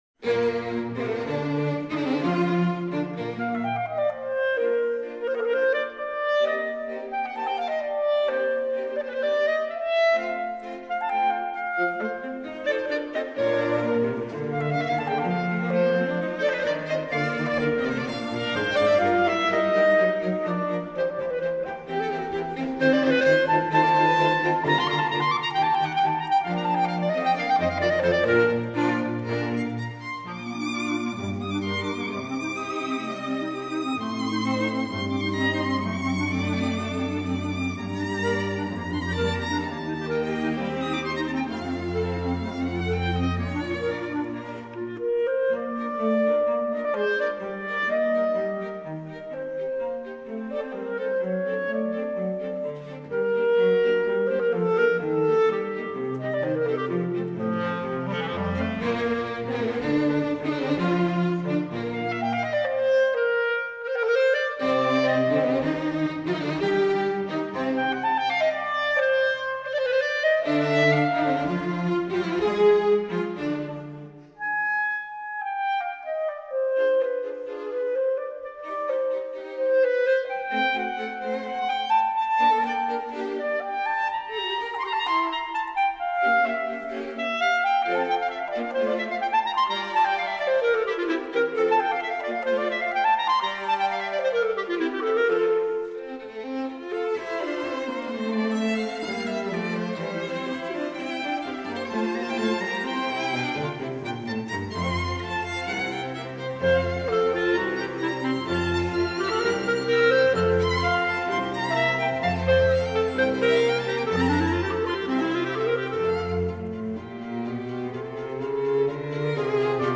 Soundbite 1st Movt